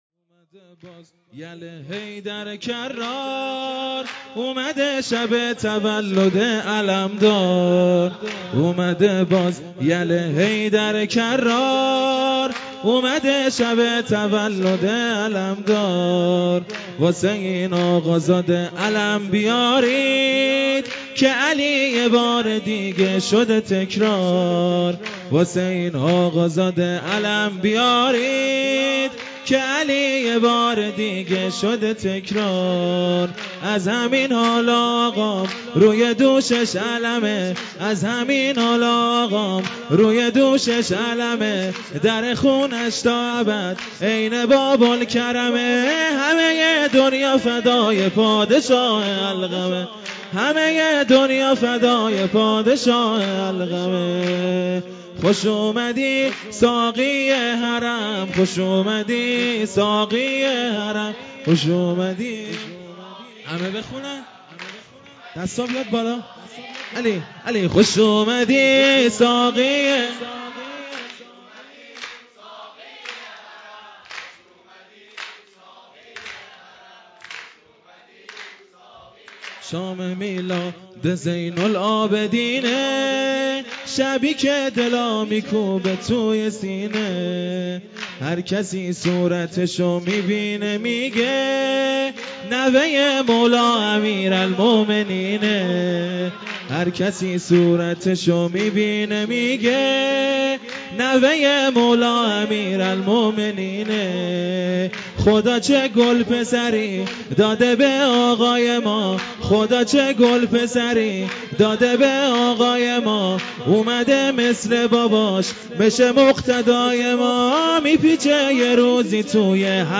ویژه برنامه جشن بزرگ اعیاد شعبانیه و میلاد انوار کربلا1403